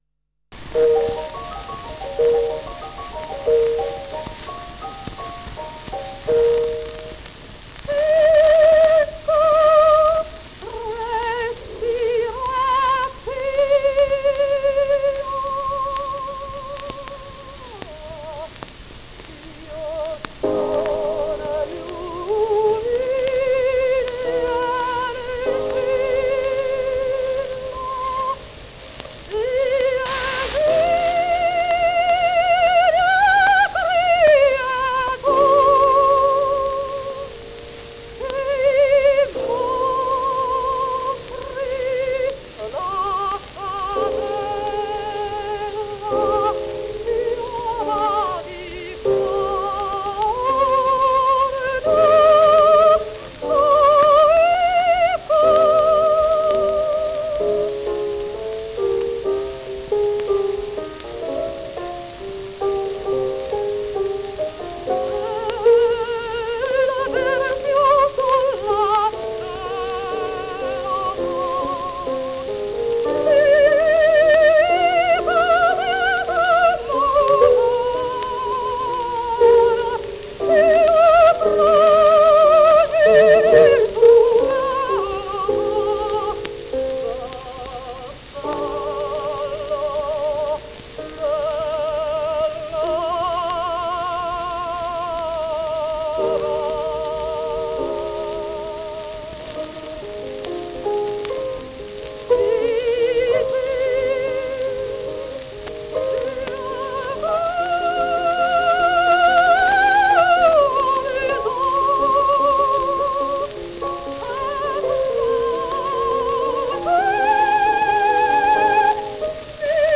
Анжелика Пандольфини (Angelica Pandolfini) (Сполето, 21 августа 1871 - Ленно, 15 июля 1959) - итальянская певица (сопрано).